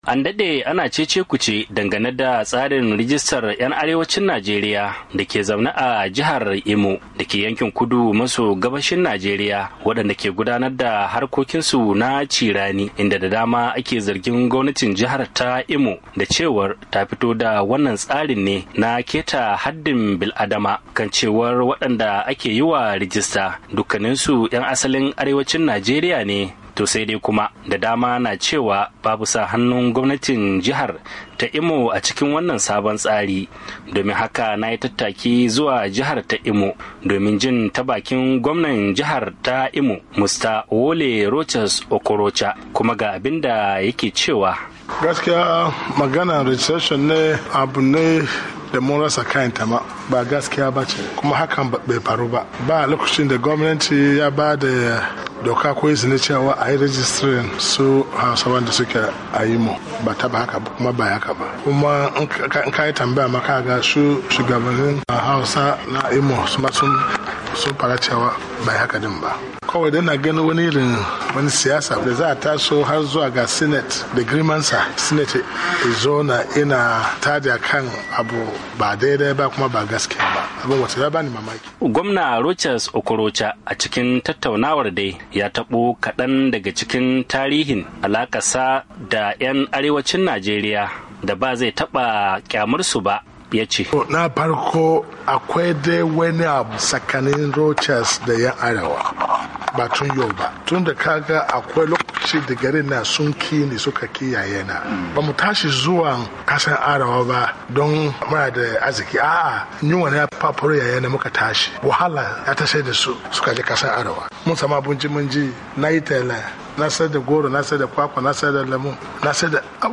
A firar da yayi da wakilin Muryar Amurka gwamnan jihar Imo Rochas Okorocha ya musanta batun yiwa 'yan asalin arewa dake jiharsa rajistan samun katin zama a jihar.